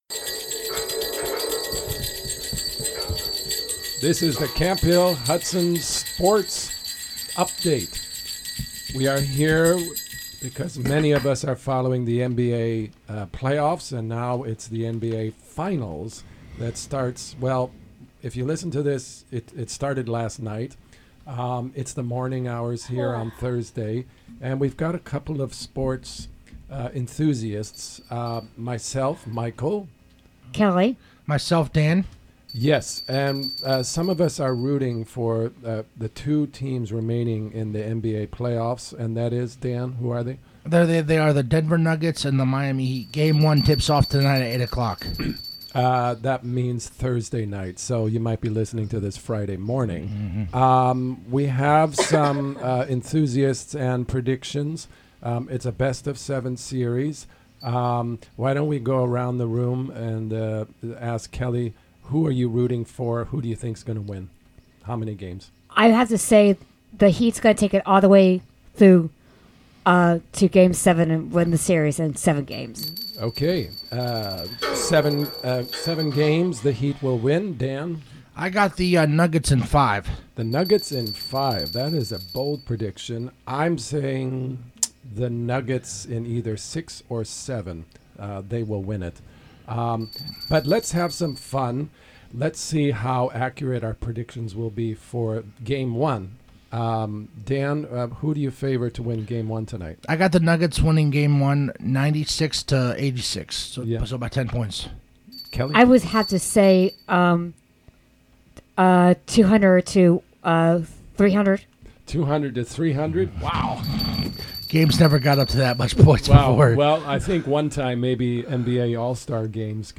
Predictions for the NBA finals, with one very accurate guess. Sports report